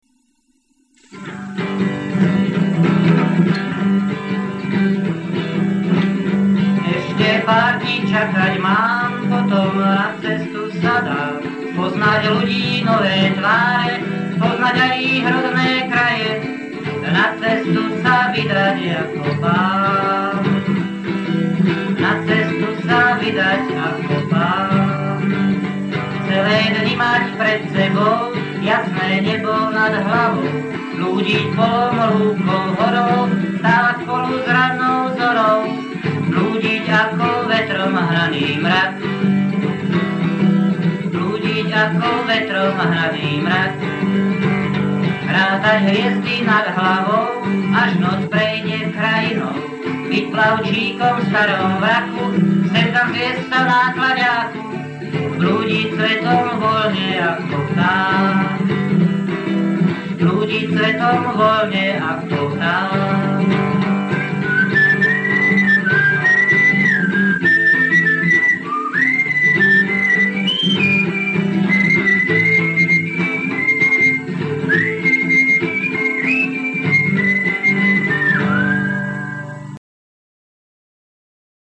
Zverejnené nahrávky piesní sú pracovné (a historické) a slúžia na správne uchopenie autorovho zámeru.